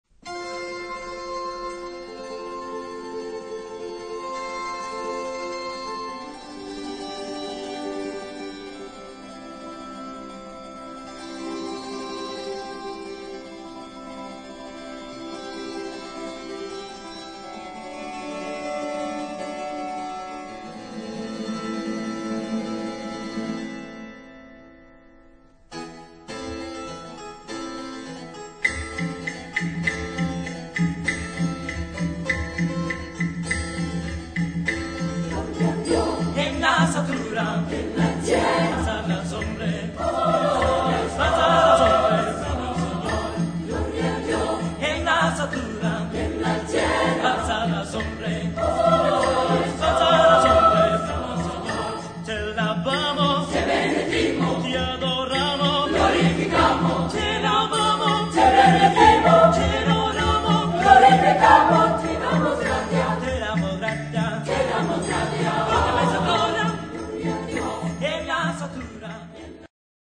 SSATB (5 voix mixtes) ; Partition complète.